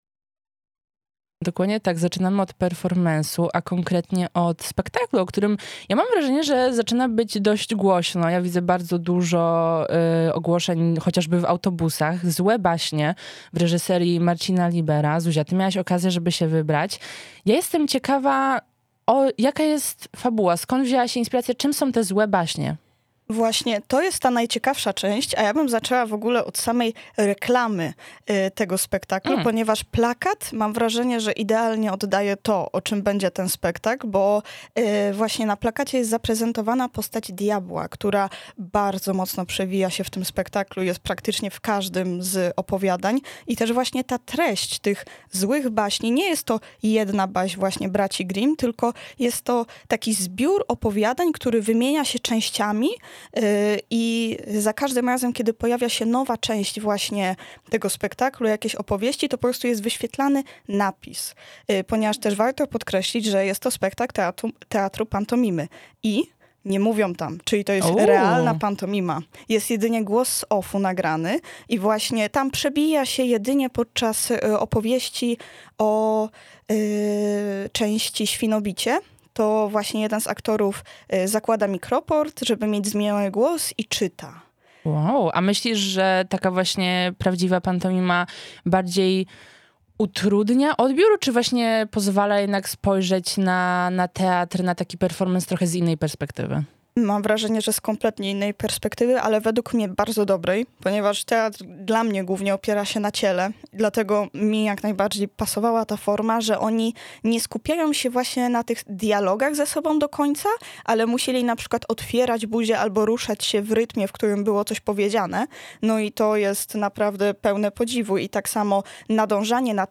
Recenzja „Złych Baśni” w reż. Marcina Libera